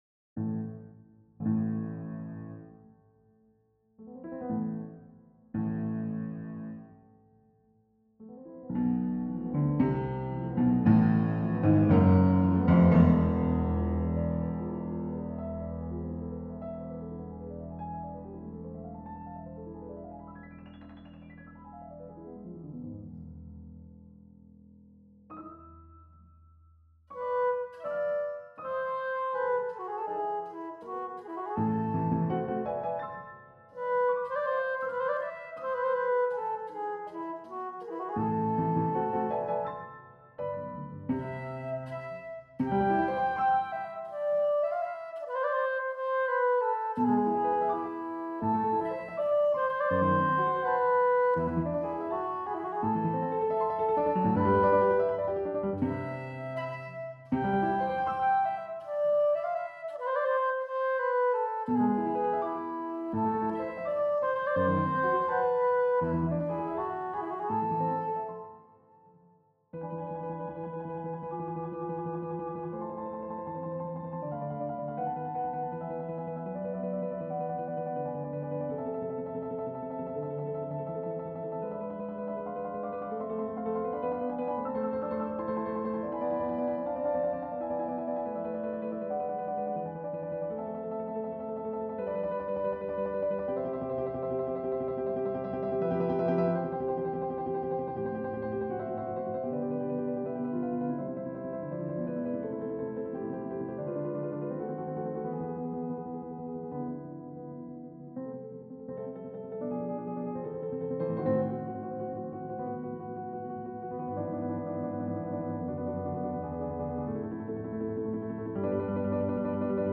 Perform your music with virtual instruments
Instruments = "Flute,Piano,Piano,Piano"